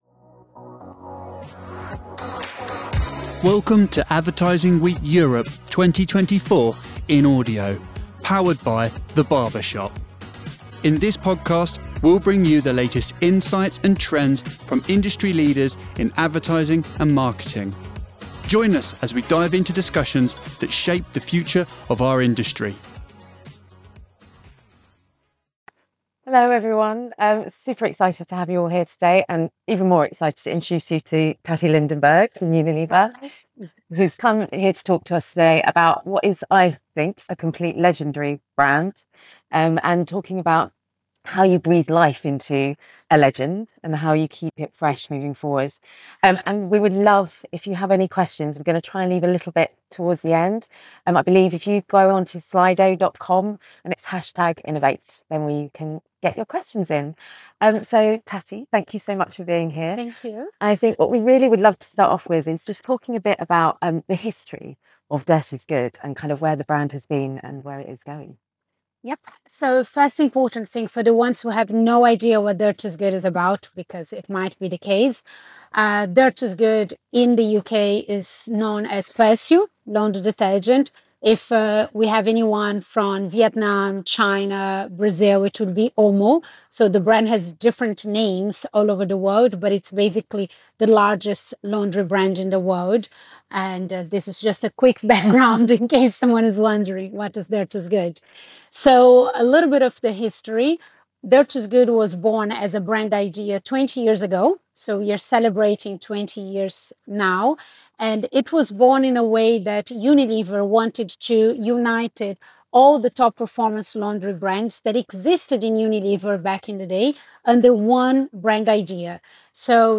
This session explores the brand's journey over the past 20 years, highlighting its commitment to adaptability, resilience, and purpose. Learn how "Dirt is Good" has stayed relevant through innovative marketing strategies, strong brand values, and a focus on sustainability. This discussion provides insights into building a long-lasting brand legacy that resonates with consumers and stands the test of time.